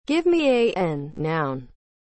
currentTTS.wav